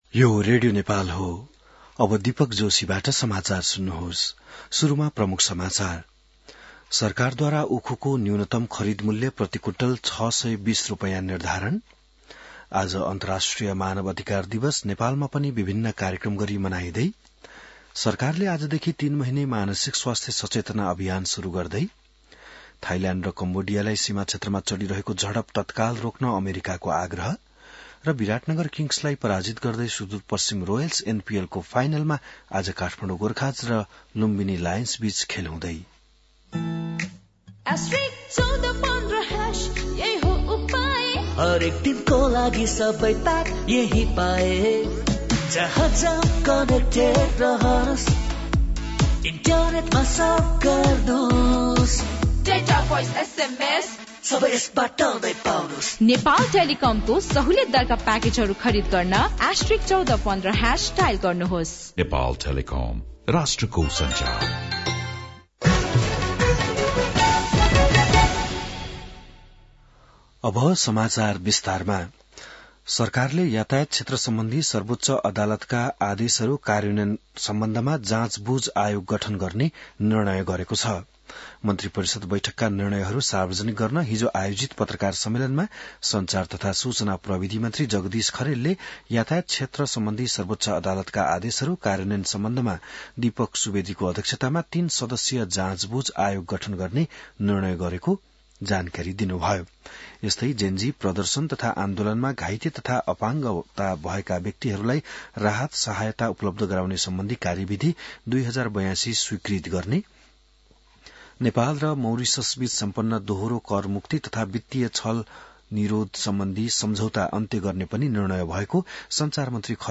बिहान ७ बजेको नेपाली समाचार : २४ मंसिर , २०८२